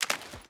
Water Jump.wav